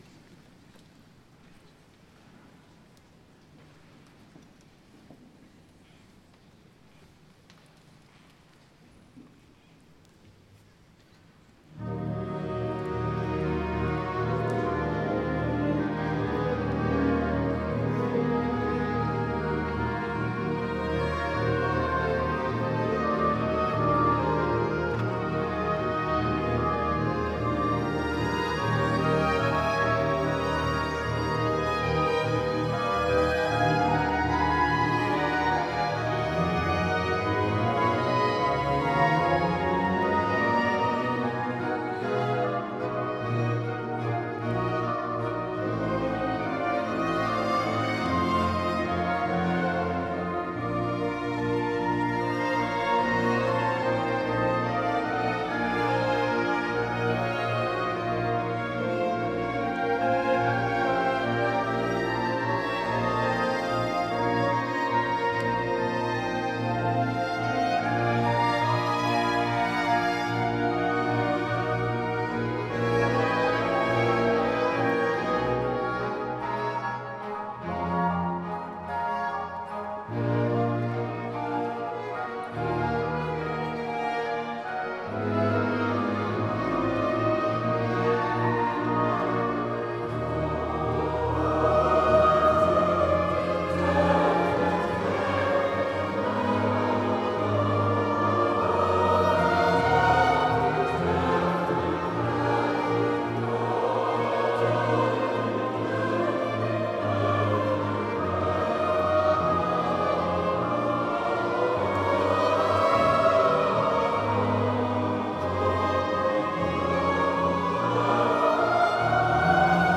Op 28 maart 2015 voerde de COV Noord-West Veluwe de Matthäus Passion van Johann Sebastian Bach uit.
Sopraan
Tenor evangelist
Bas Christus